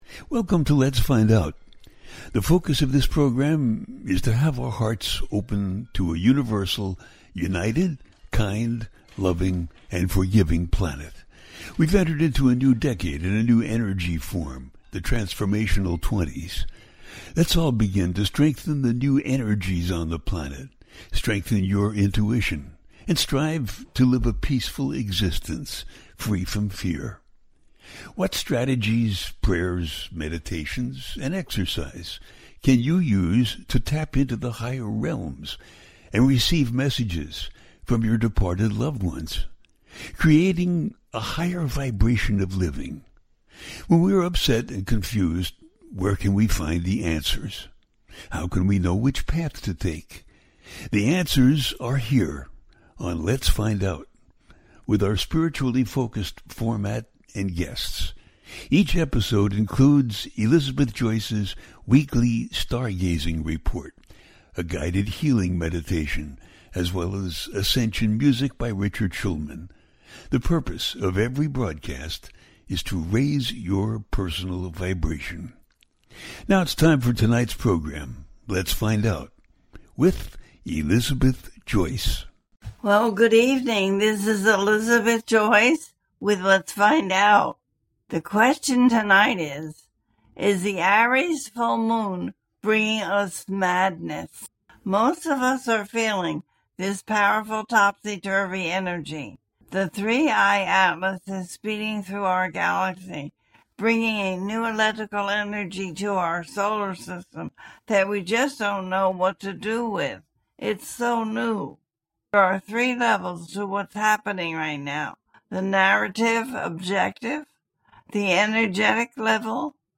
Is The Aries Full Moon Bringing Us Madness - A teaching show
The listener can call in to ask a question on the air.
Each show ends with a guided meditation.